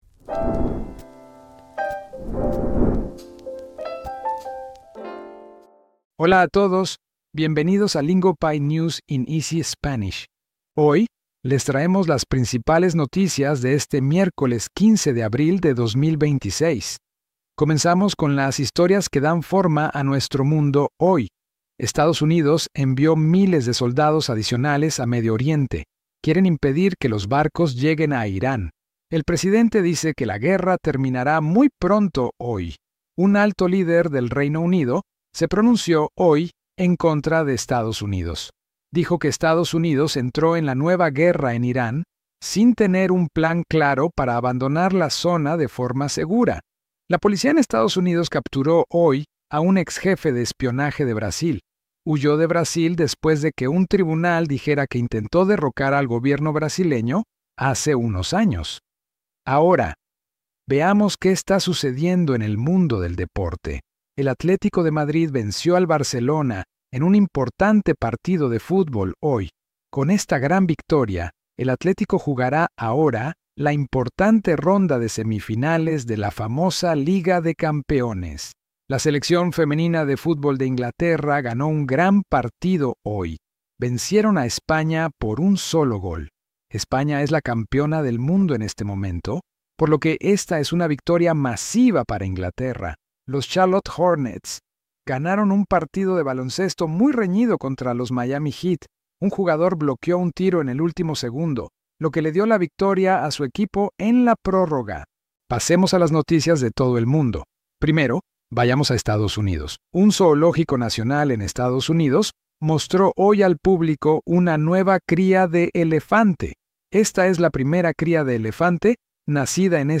Today’s news has everything from war moves to a wild tech failure. We break it down in clear, beginner-friendly Spanish so you can follow the main stories without getting lost.